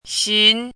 chinese-voice - 汉字语音库
xin2.mp3